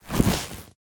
harness_equip.ogg